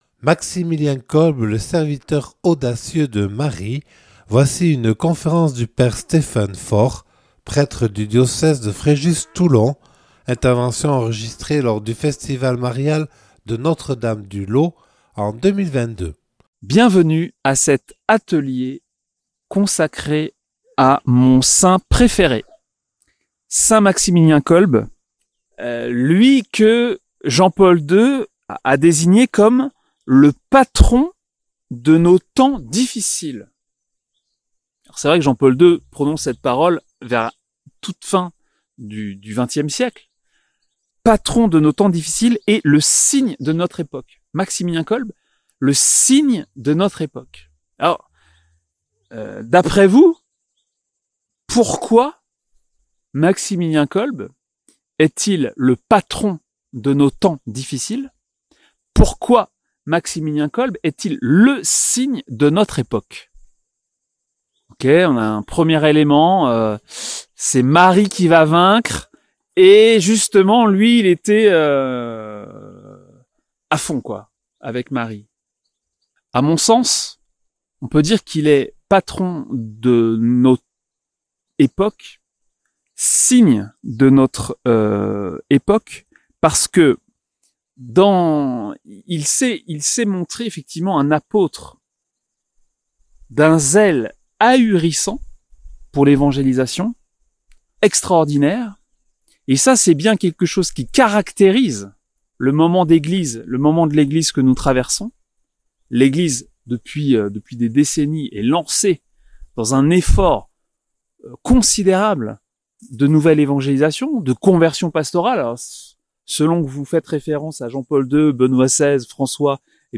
Notre-Dame du laus Festival Marial